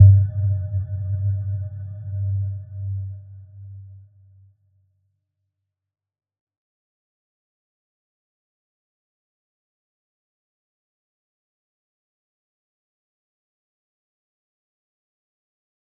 Little-Pluck-G2-mf.wav